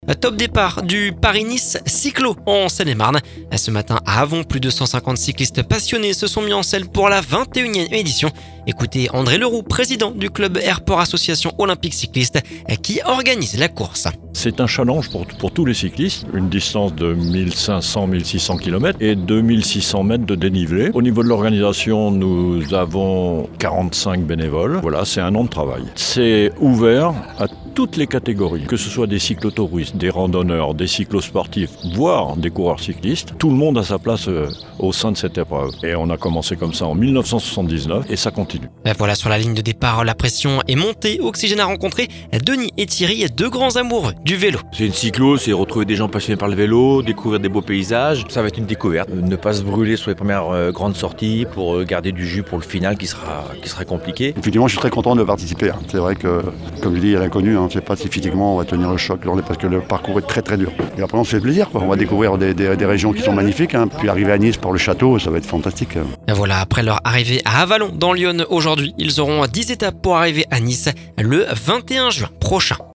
*Reportage